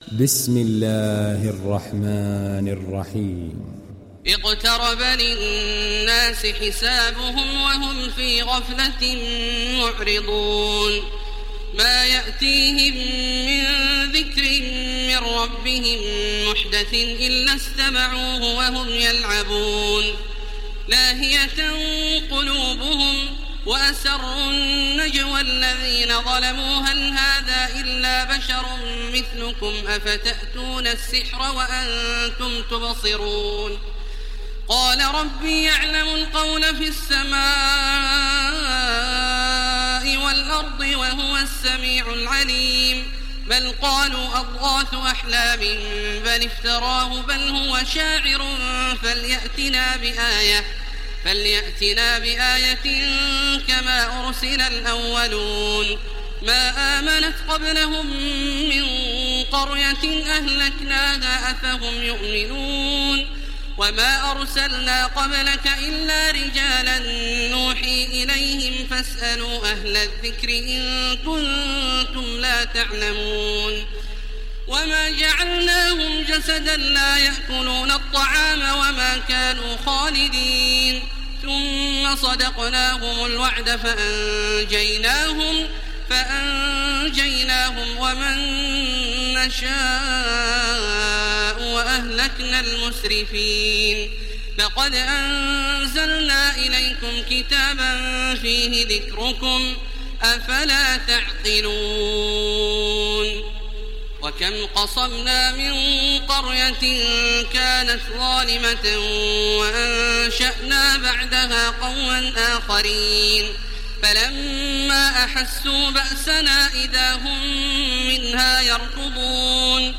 Download Surat Al Anbiya Taraweeh Makkah 1430